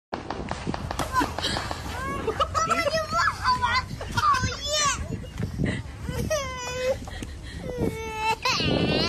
SFX一点都不好玩讨厌音效下载
SFX音效